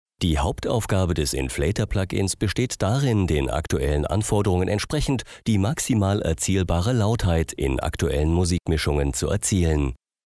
deutscher Sprecher.
Sprechprobe: Industrie (Muttersprache):
german voice over artist